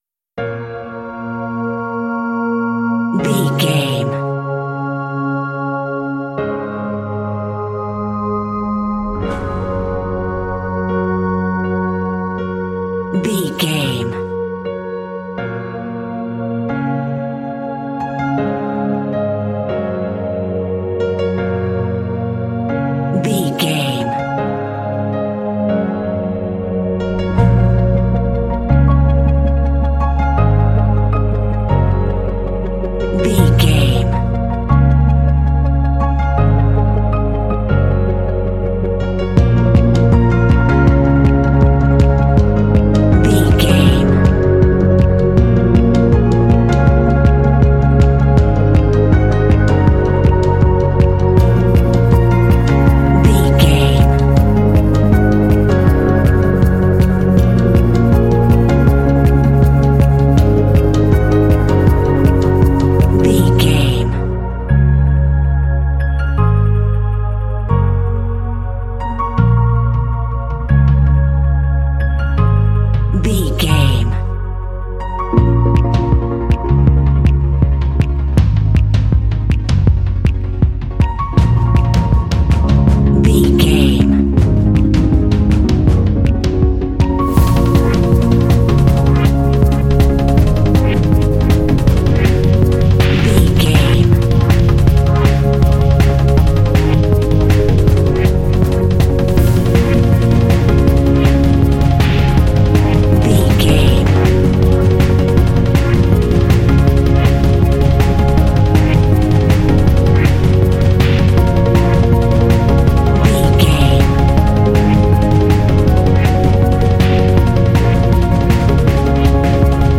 In-crescendo
Aeolian/Minor
B♭
Fast
driving
energetic
piano
percussion
drums
strings
synthesiser
synth-pop